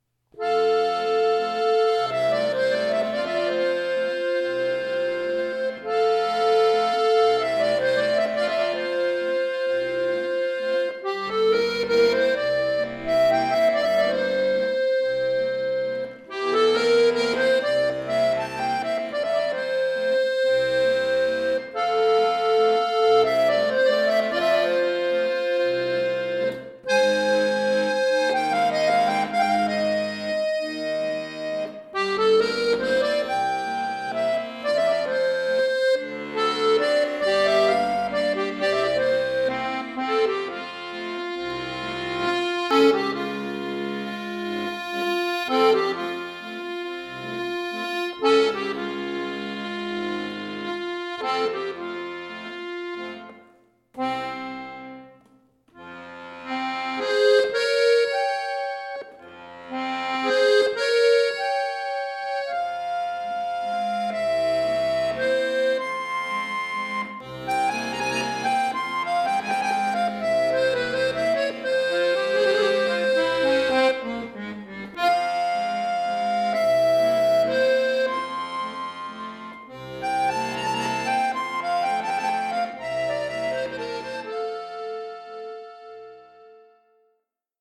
Bekannter spanischer Walzer
in einem Arrangement für Akkordeon solo